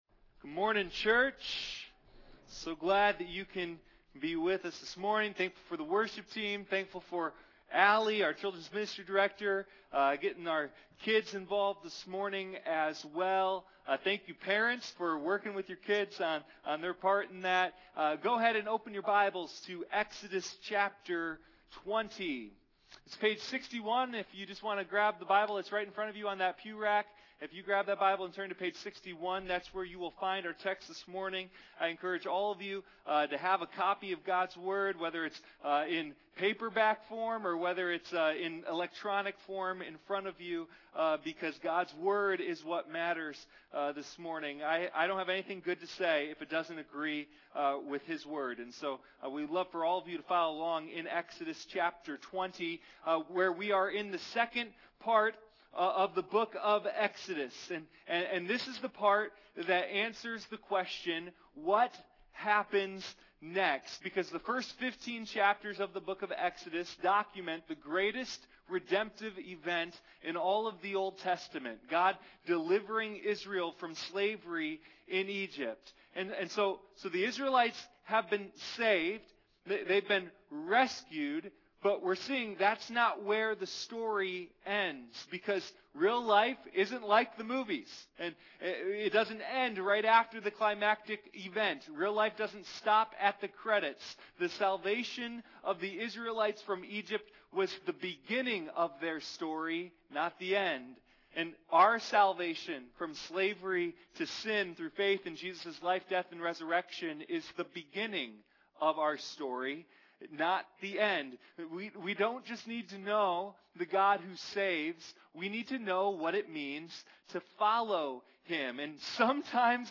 Sunday Morning Communion